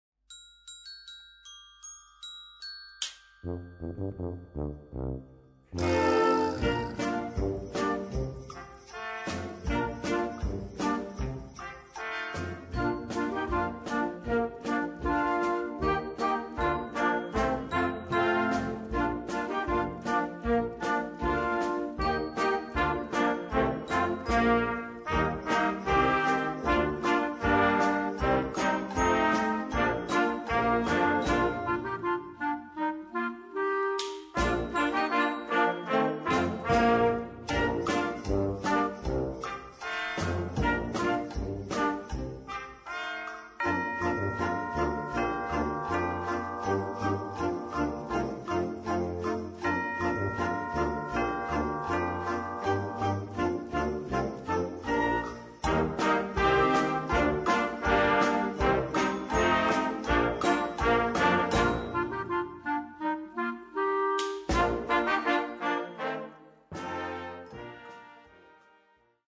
Sinterklaas und Weihnachtsmusik
Noten für flexibles Ensemble, 4-stimmig + Percussion.